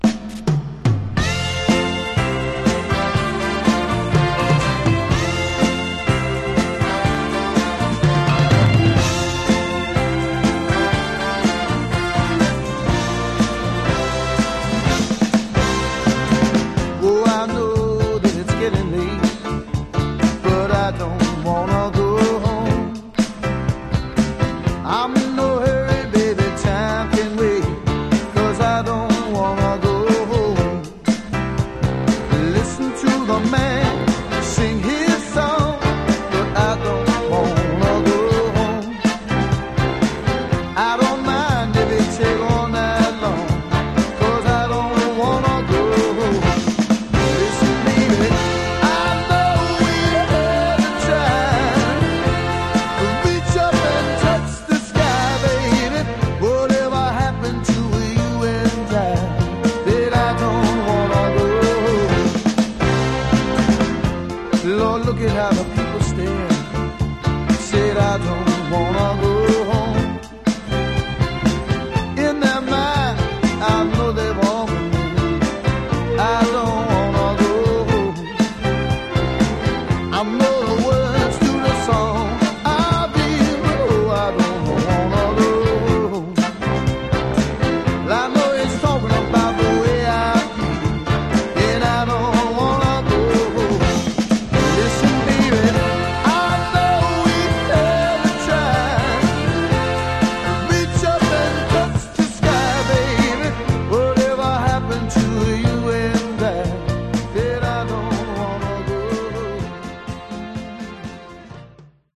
Genre: Horn Rock